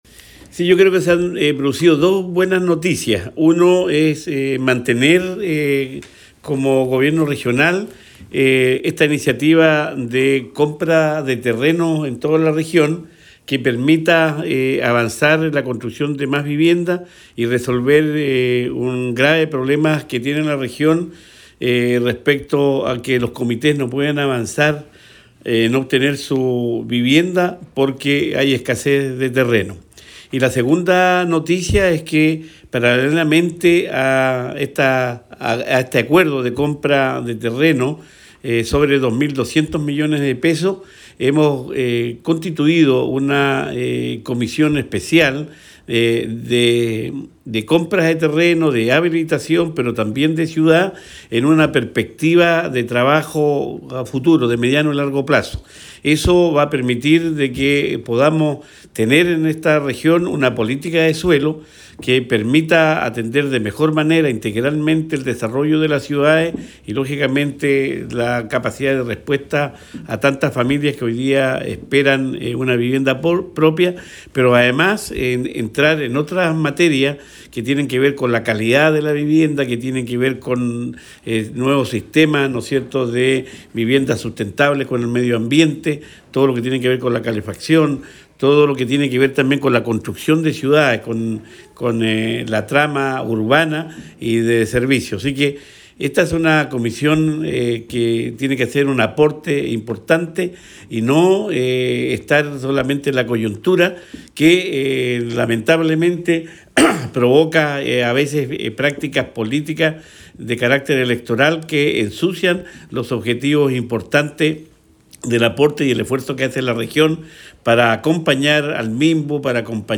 Cuña_Gobernador-Luis-Cuvertino_adquisición-de-terrenos.mp3